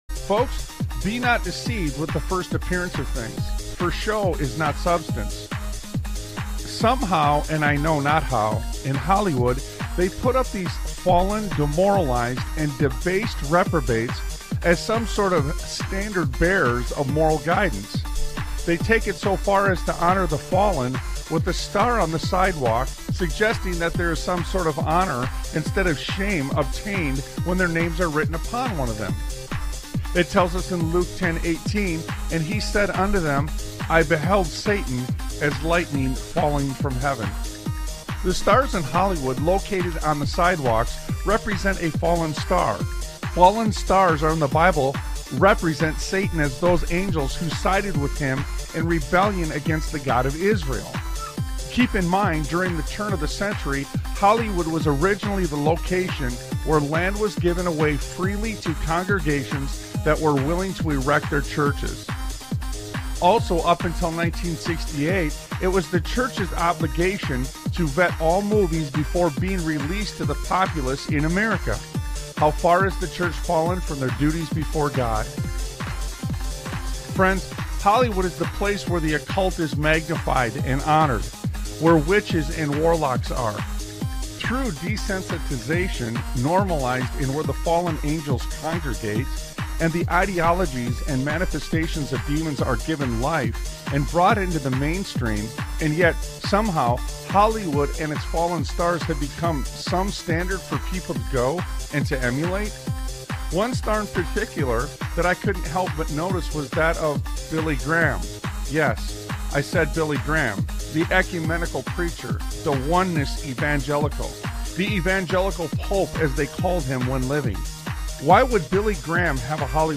Talk Show Episode, Audio Podcast, Sons of Liberty Radio and Full Circle on , show guests , about Full Circle, categorized as Education,History,Military,News,Politics & Government,Religion,Christianity,Society and Culture,Theory & Conspiracy